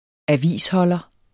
Udtale [ -ˌhʌlʌ ]